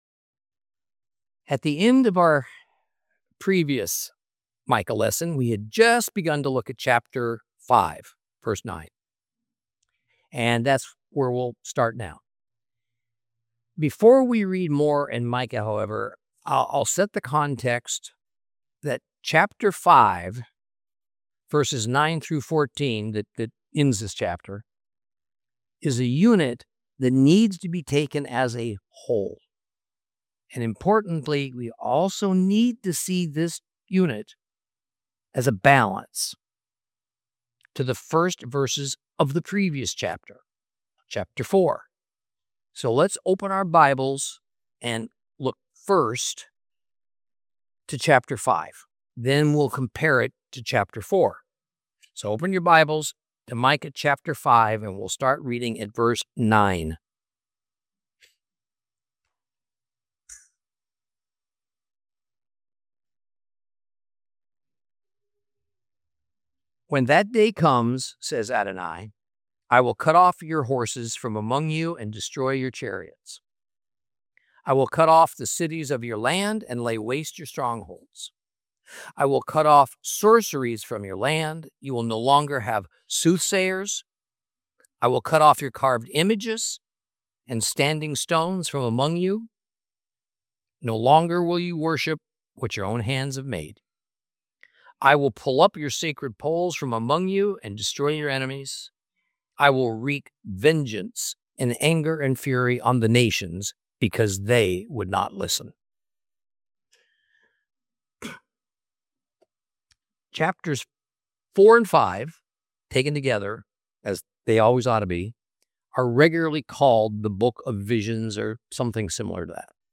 Teaching from the book of Micah, Lesson 10 Chapters 5 and 6.